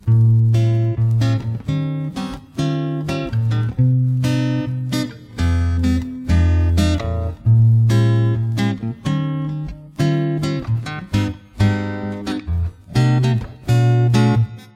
130 Bpm 波萨吉他3
描述：博萨的节奏。和弦：Am7/D7 D9/Bm7/Em Em7/Am7/D7 D9/Gmaj7/Bm7 Bbm7
标签： 130 bpm Jazz Loops Guitar Acoustic Loops 2.49 MB wav Key : G
声道立体声